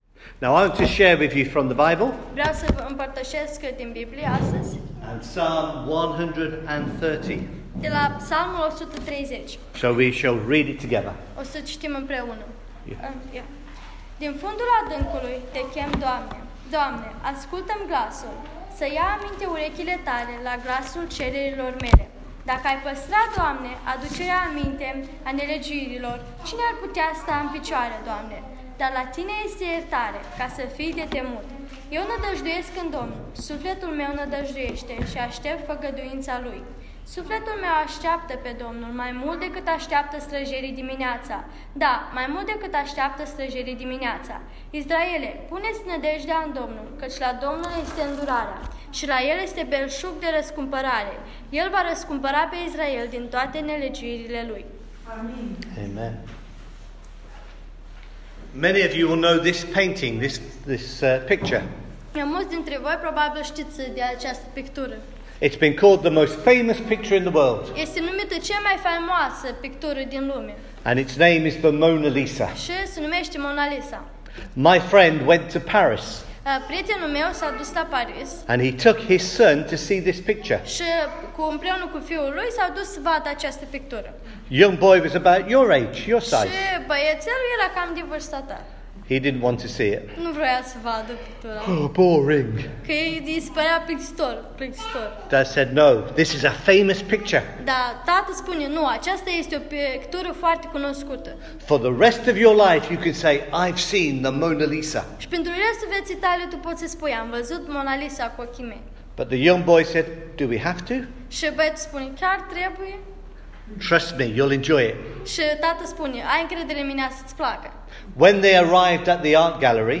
sermon
Moldovan/Romanian language Translator